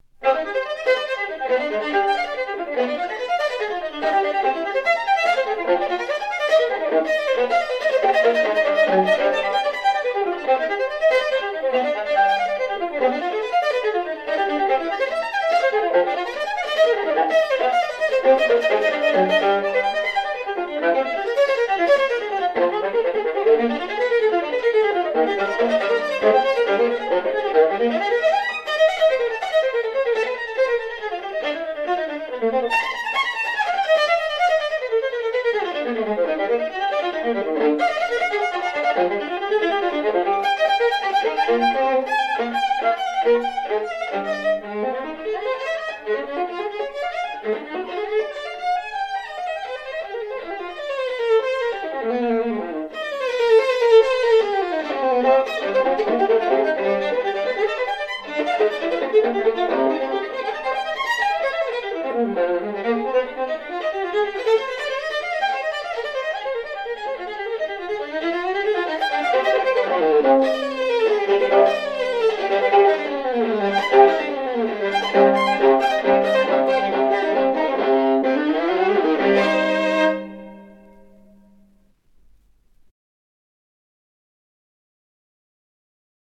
Viola